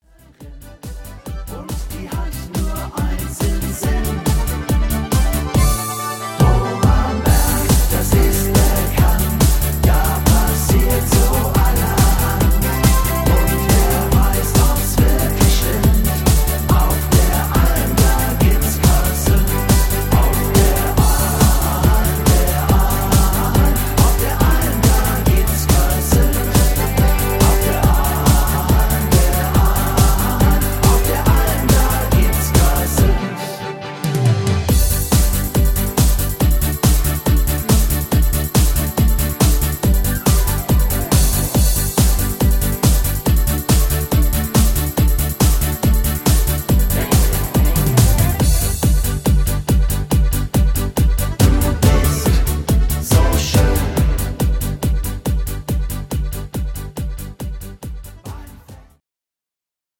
Rhythmus  Party Polka Rock